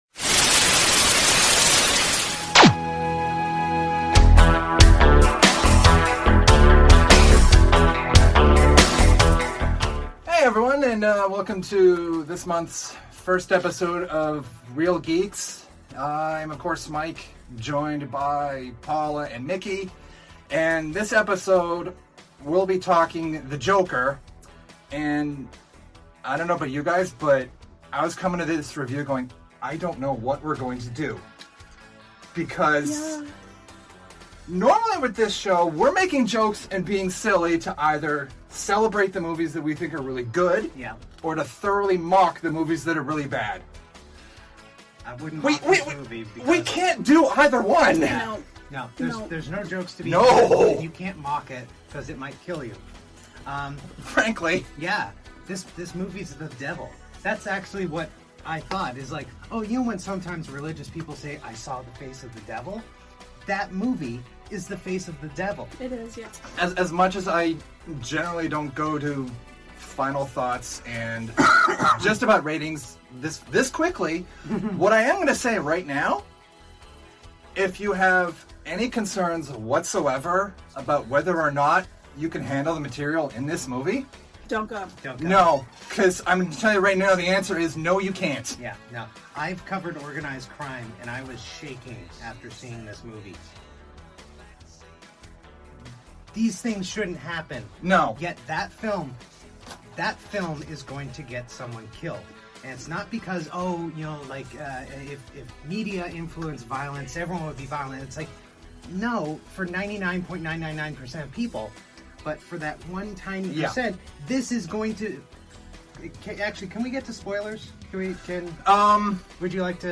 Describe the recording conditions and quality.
Originally recorded in Halifax, NS, Canada